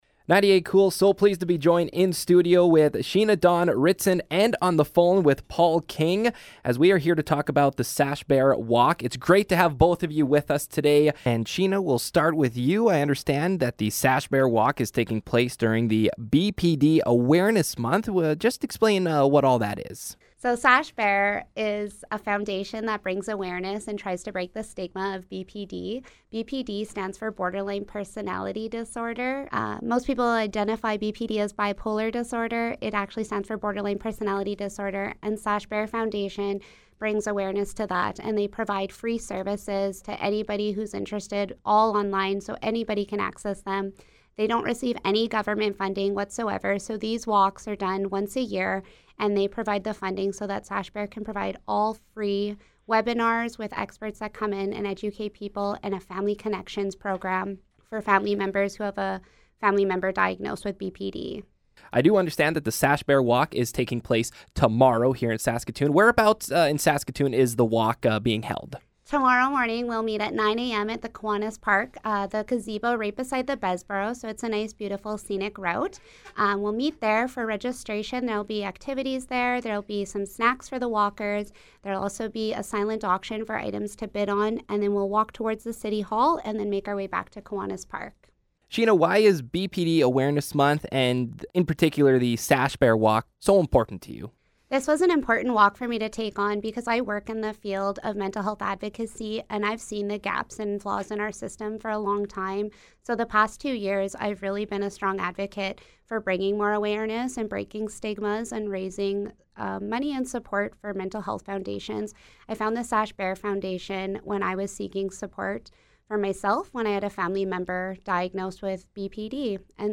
joined us to share some details!